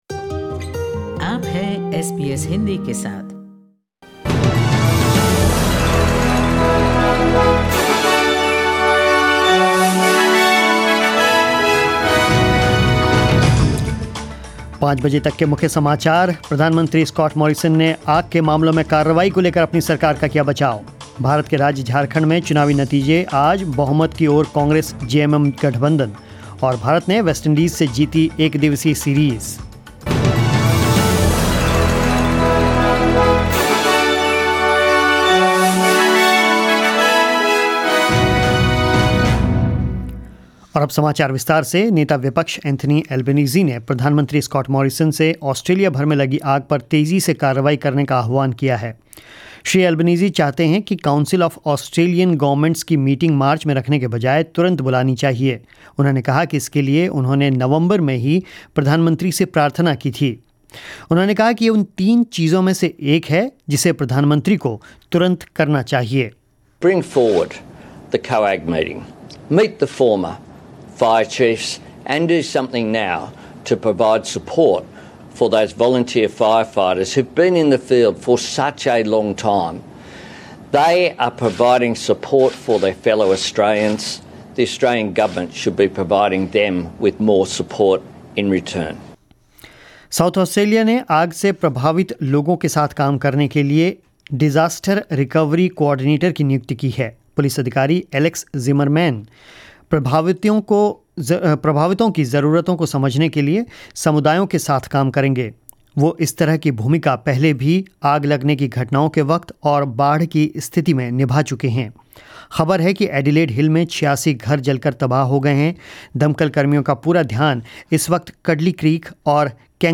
News in Hindi